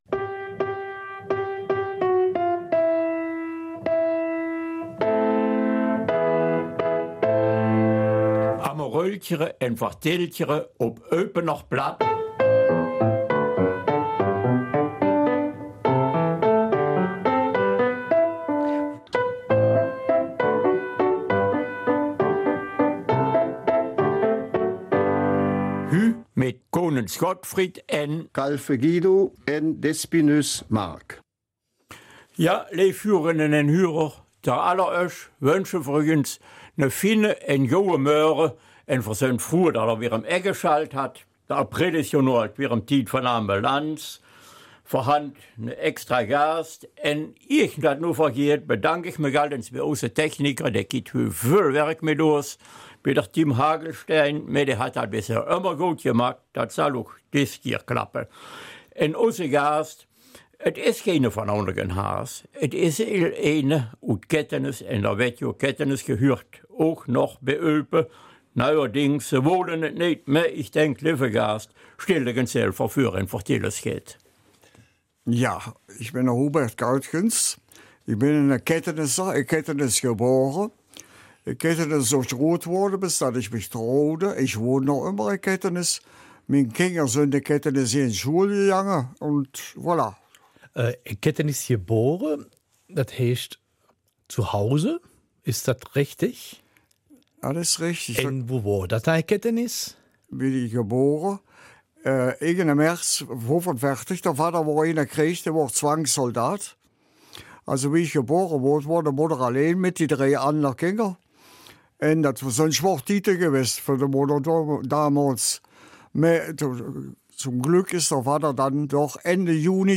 Eupener Mundart: Jeder ist herzlich willkommen